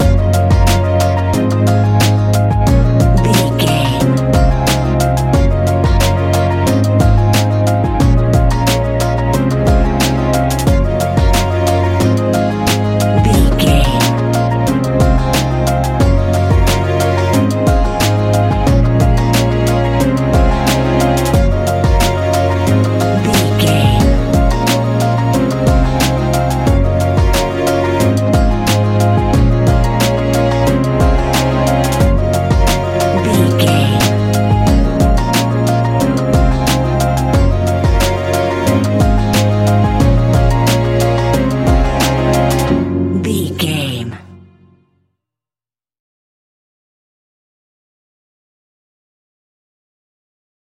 Ionian/Major
C♭
Lounge
sparse
new age
chilled electronica
ambient
atmospheric
instrumentals